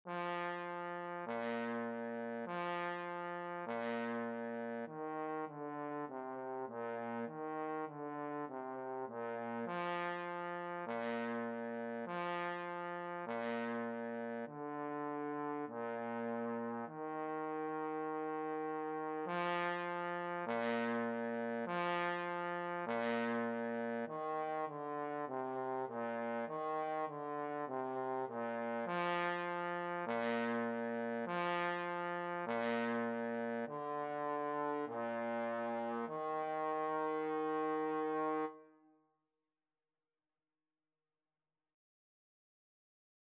4/4 (View more 4/4 Music)
Bb3-F4
Trombone  (View more Beginners Trombone Music)
Classical (View more Classical Trombone Music)